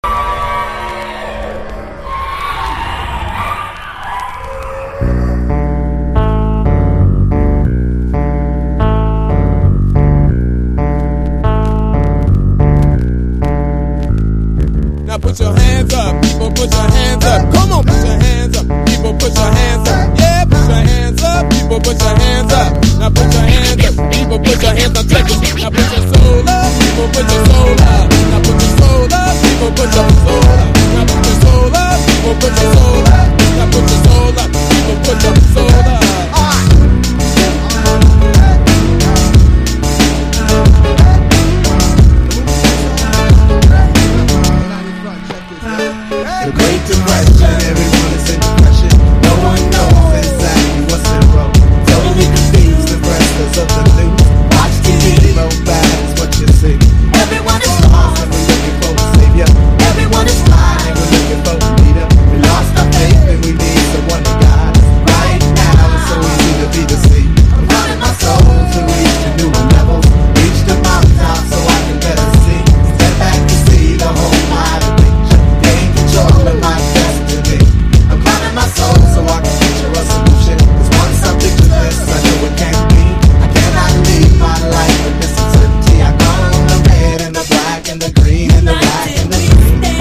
所によりノイズありますが、リスニング用としては問題く、中古盤として標準的なコンディション。